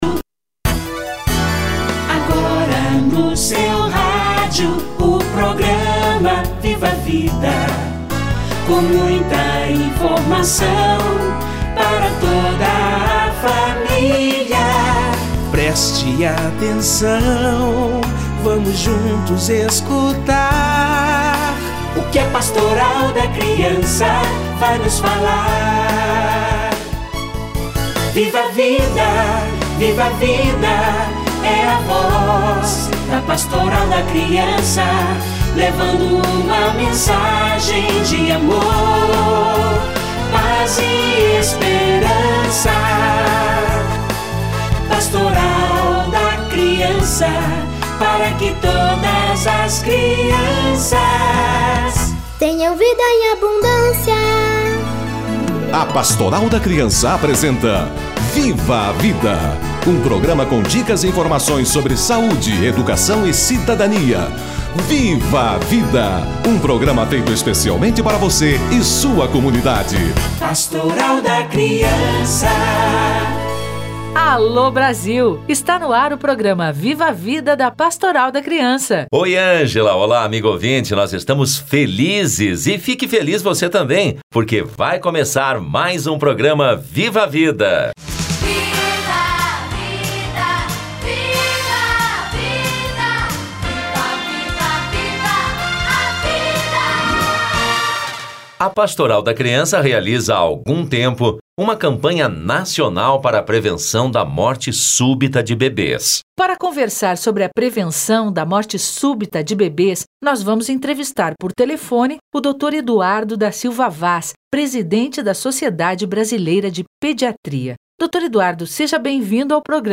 Bebê deve dormir de barriga para cima - Entrevista